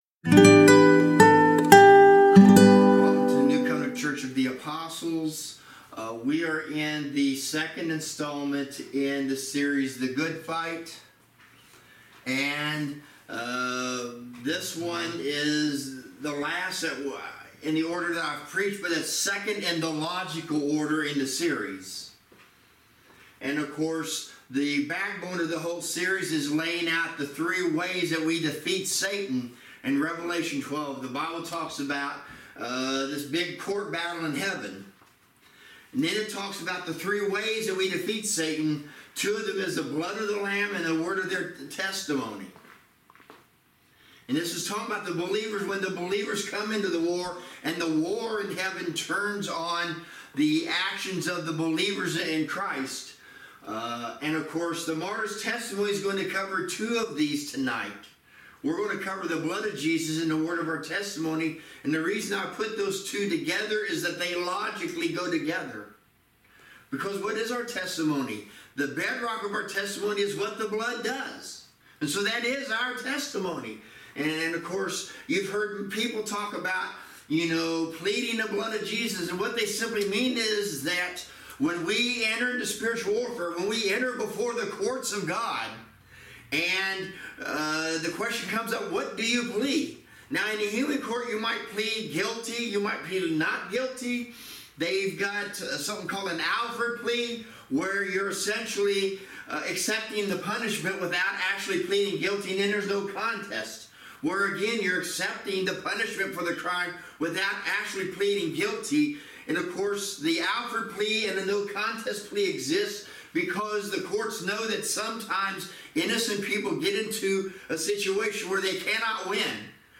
Service Type: Sunday Service